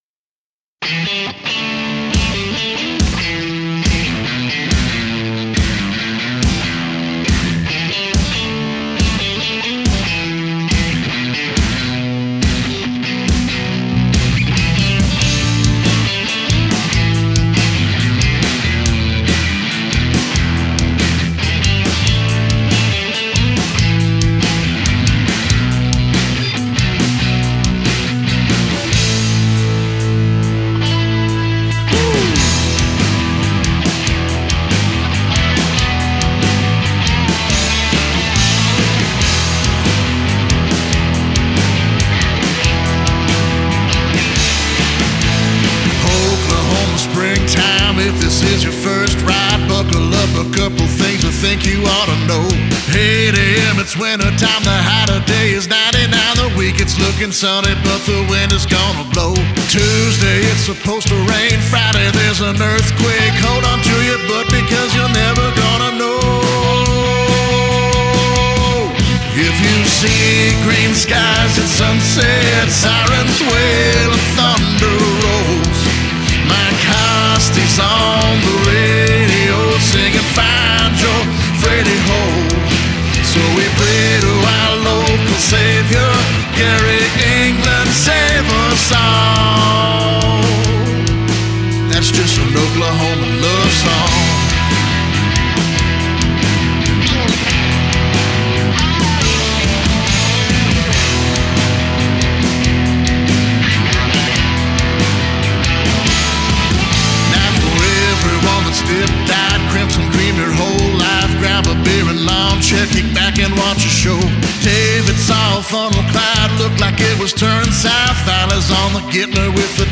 Vocals, Guitars, Bass, Keys
Drums